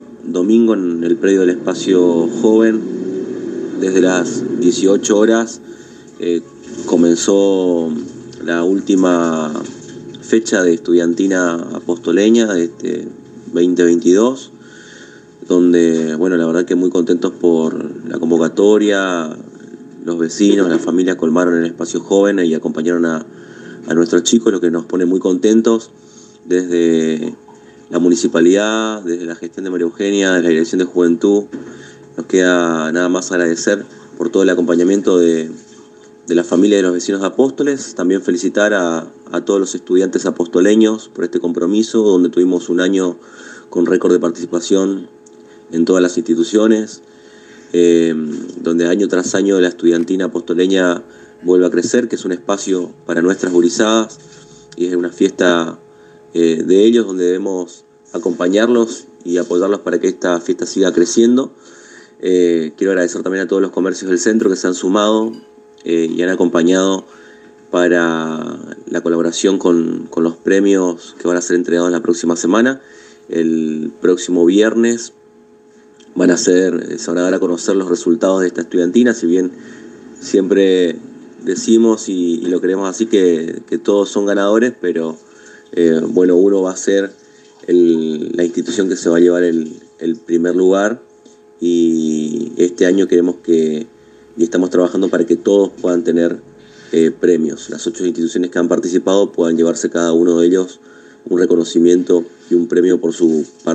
Aldo Muñoz Director de la Juventud de Apóstoles en diálogo exclusivo con la ANG comentó sobre el cierre de la Estudiantina Apostoleña 2022 que se realizó el domingo pasado en el Espacio Joven.